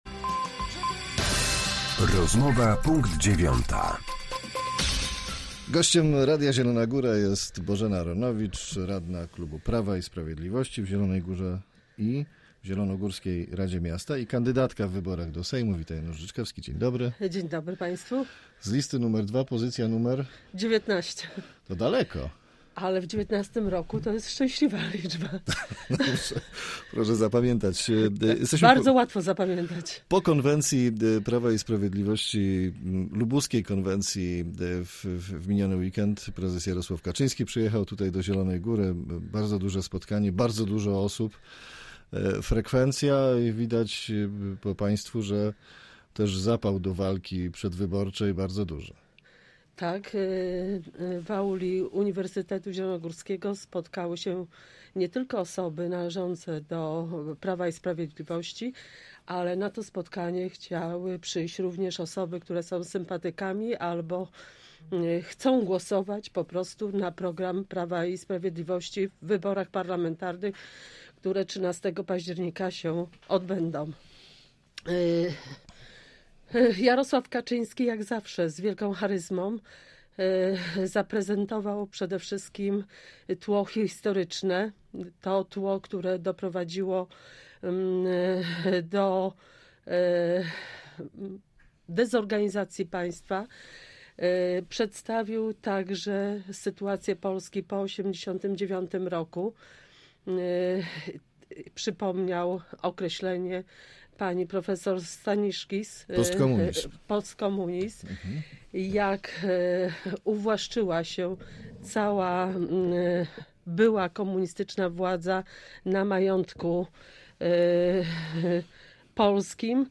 Z radną klubu Prawo i Sprawiedliwość rozmawiał